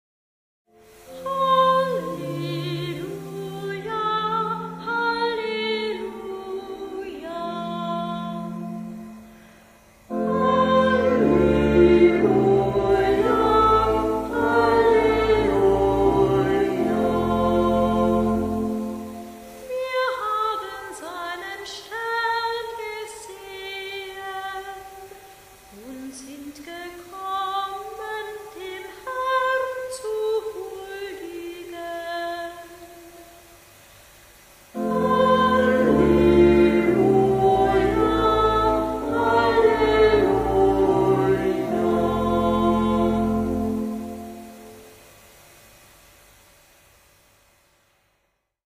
Ruf vor dem Evangelium 759 KB 6.
Orgel